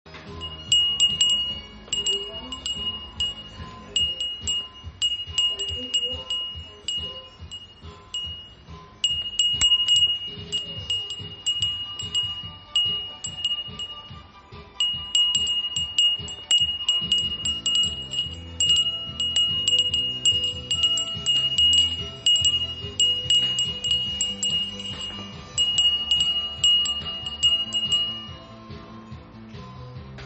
陶器製丸風鈴クレマチス赤
品名 陶器製丸風鈴クレマチス赤 サイズ 玉の高さ約6.5cm 箱入り （数量割引有り）